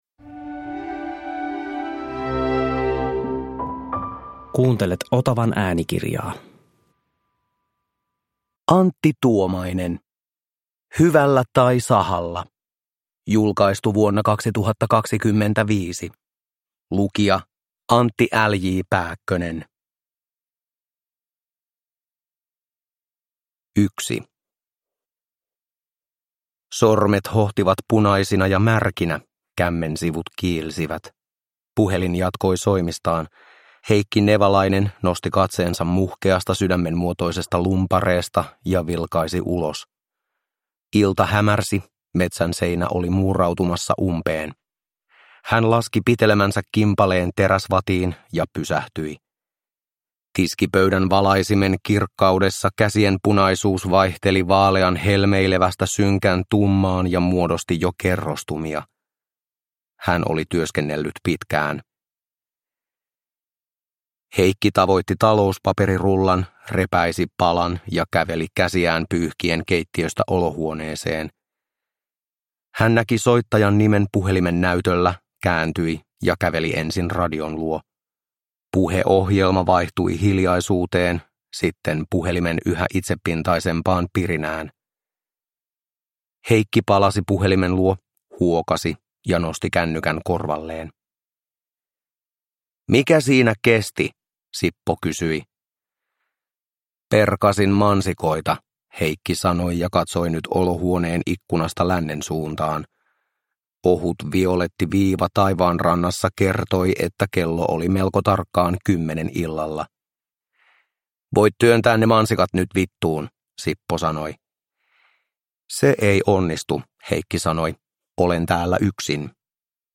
Hyvällä tai sahalla – Ljudbok